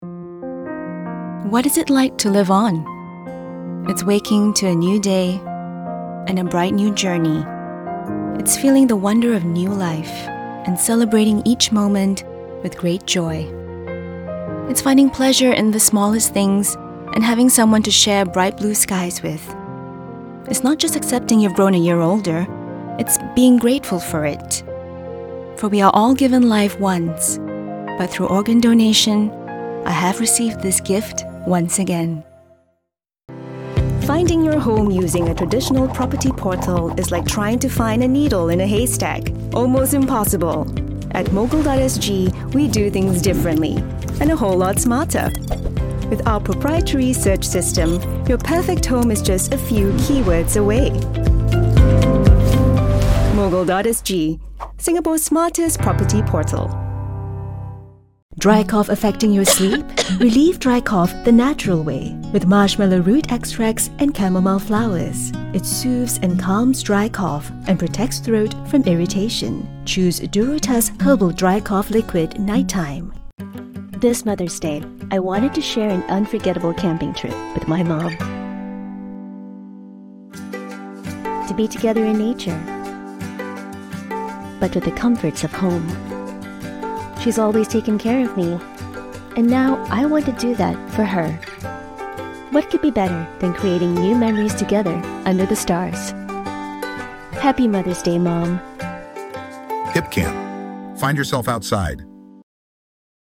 Foreign & British Female Voice Over Artists & Actors
Bahasa (Malaysia)
Adult (30-50) | Yng Adult (18-29)